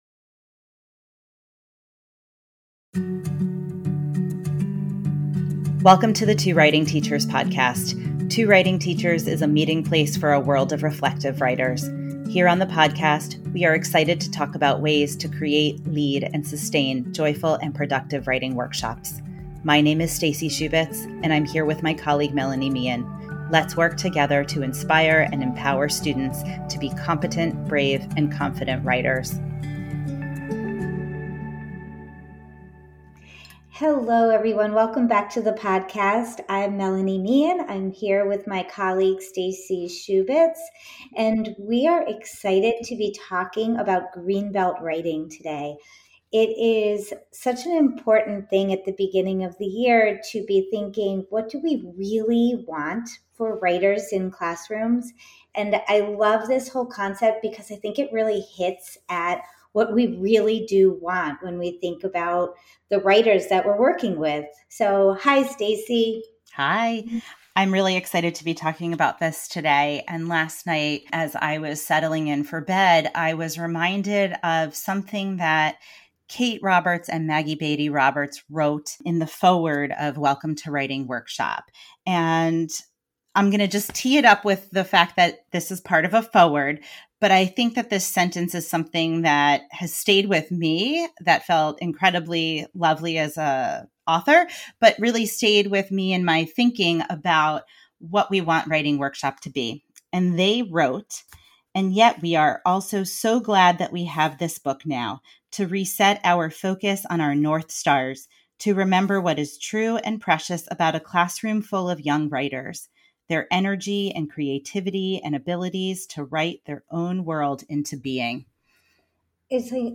Activism in Writing: A Writing Roundtable – Two Writing Teachers Podcast